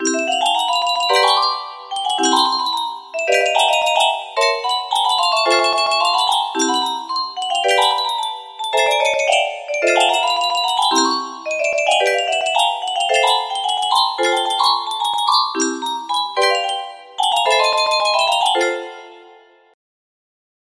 music box melody
Grand Illusions 30 (F scale)